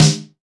PSNARE 1.wav